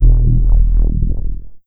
Index of /cont/sounds/weapon/laser
pulse_laser2.wav